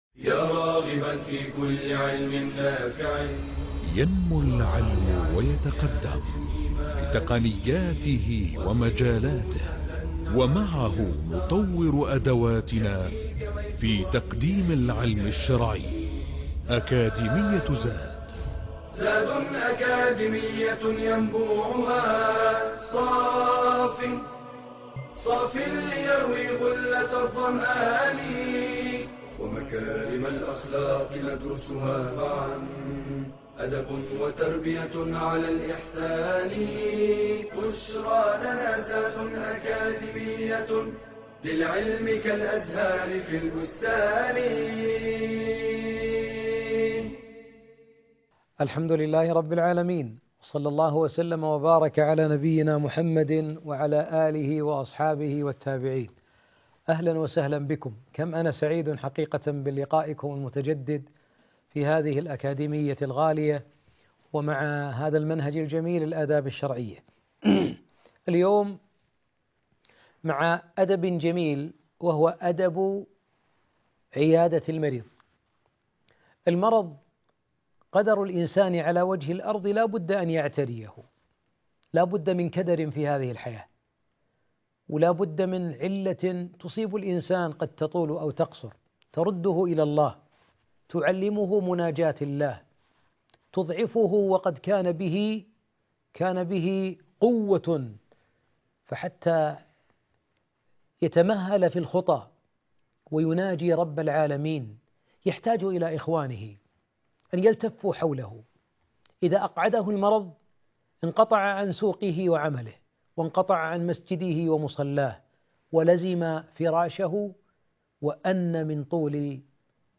المحاضرة الرابعة عشر _عيادة المرضى